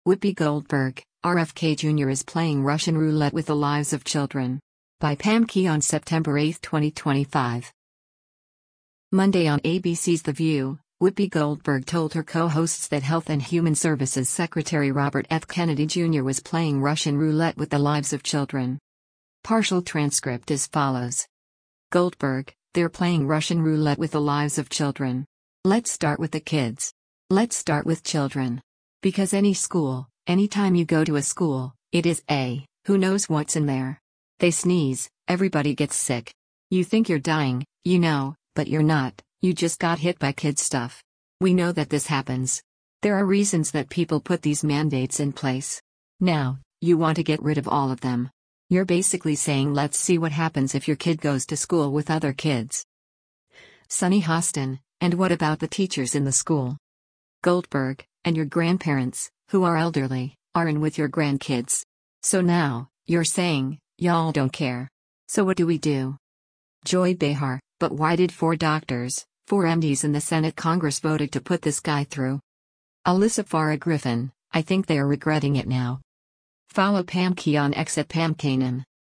Monday on ABC’s “The View,” Whoopi Goldberg told her co-hosts that Health and Human Services Secretary Robert F. Kennedy Jr. was “playing Russian roulette” with the lives of children.